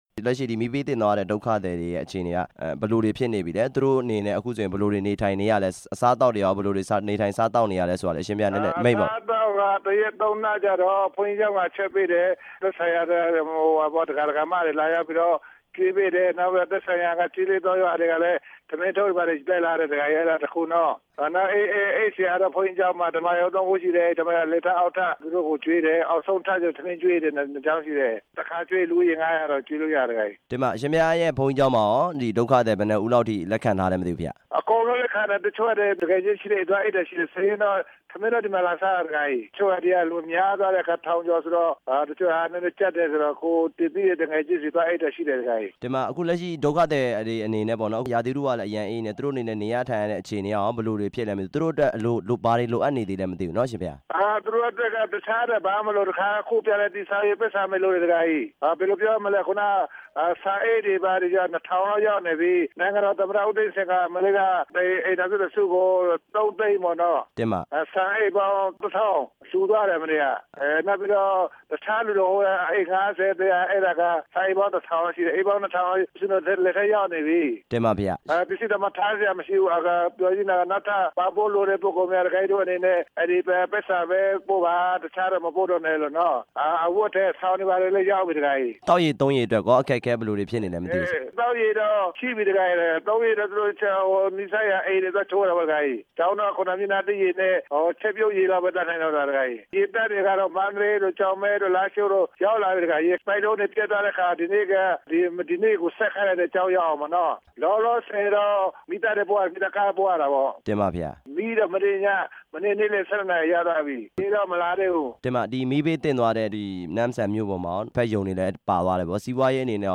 နမ့်ဆန်မြို့က မီးဘေးသင့် ပြည်သူတွေအကြောင်း မေးမြန်းချက်